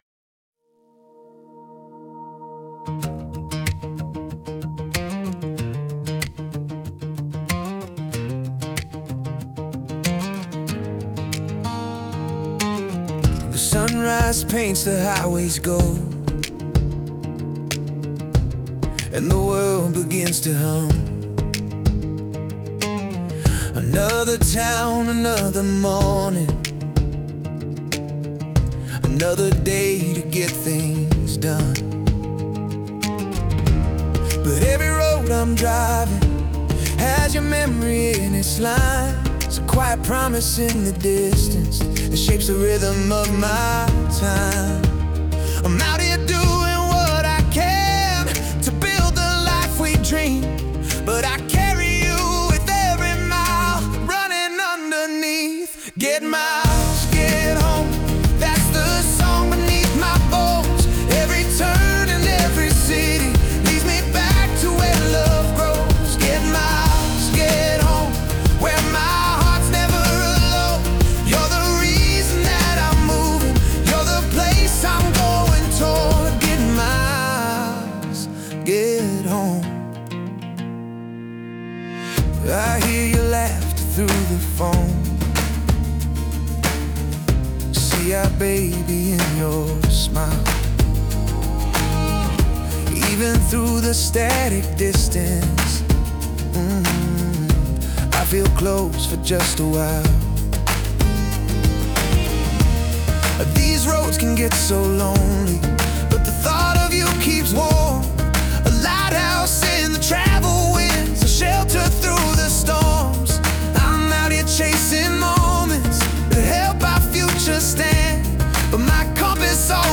Explore Our Acoustic Tracks